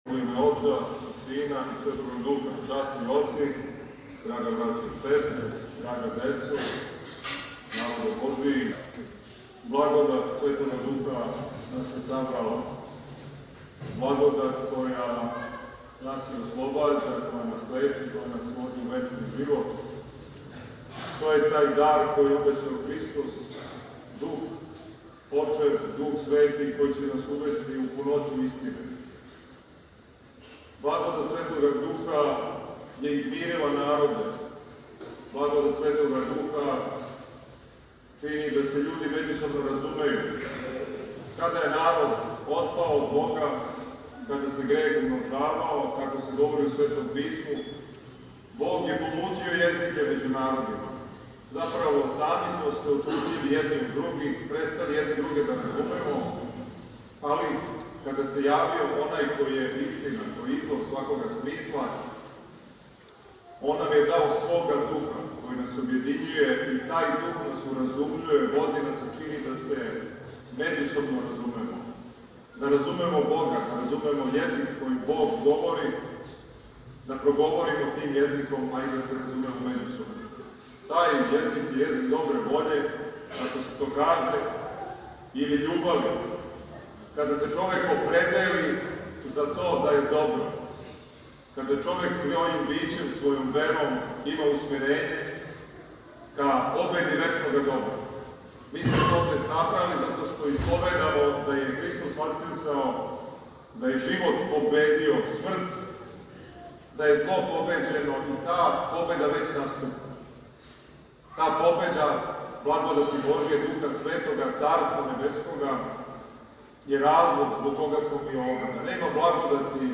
Његово Преосвештенство викарни Епископ новобрдски г. Иларион је данас, 4. јуна 2023. године, на празник Педесетнице - Силаска Светога Духа на Апостоле, а ове године и на дан када молитвено обележавамо спомен св. Јована Владимира, служио свету Литургију у храму посвећеном овом мученички пострадалом српском владару у београдском насељу Медаковић.
Звучни запис беседе